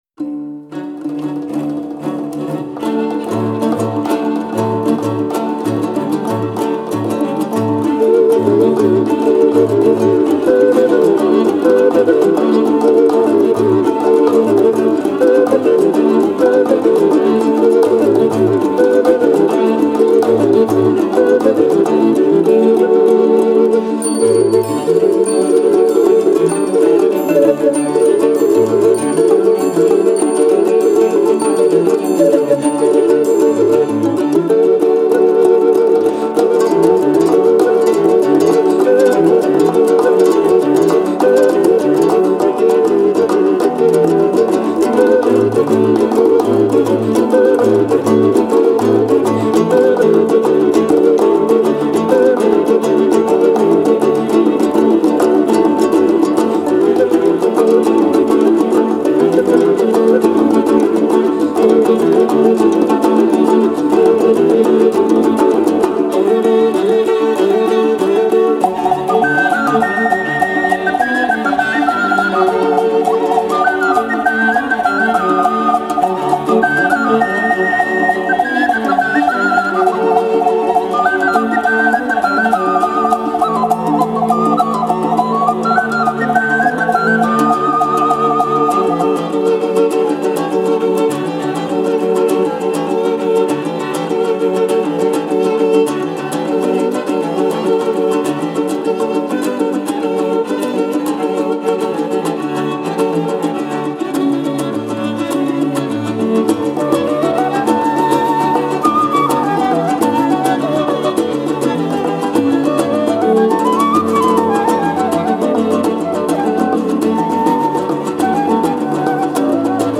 Трек размещён в разделе Русские песни / Киргизская музыка.